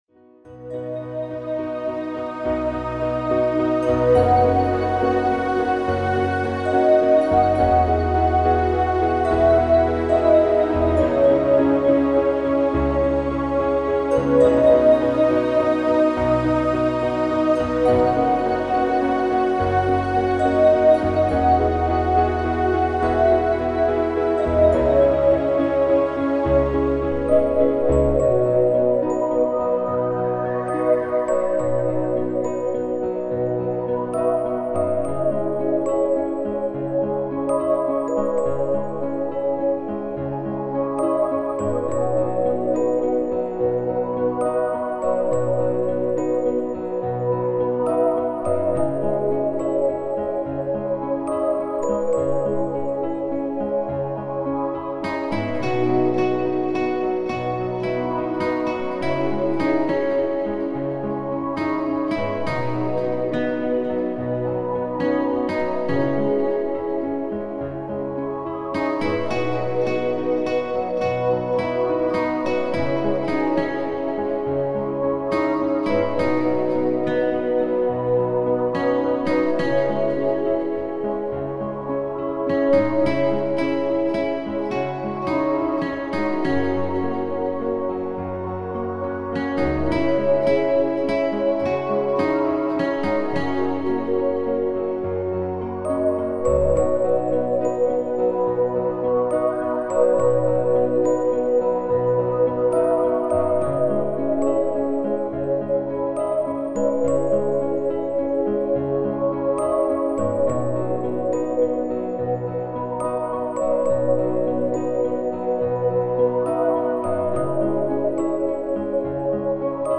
Фонограмма: